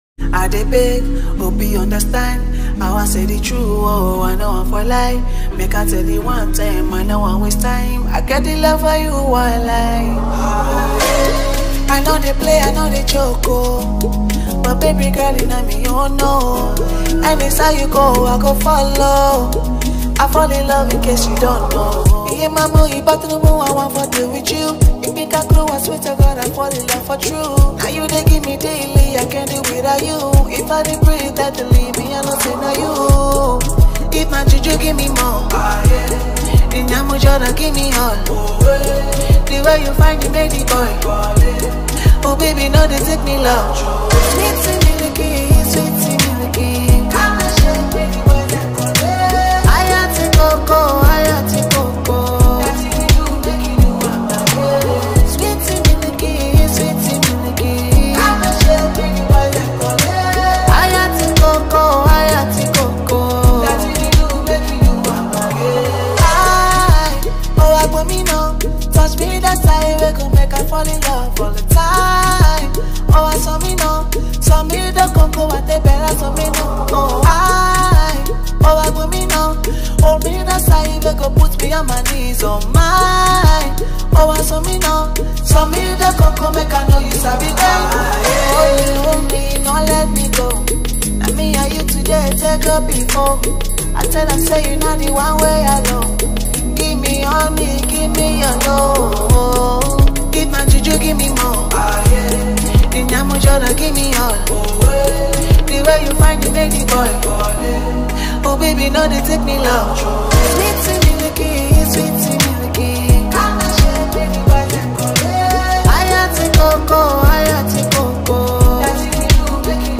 emotional love song
an Afrobeat
rhythmic patterns influenced by bubu and Afrobeat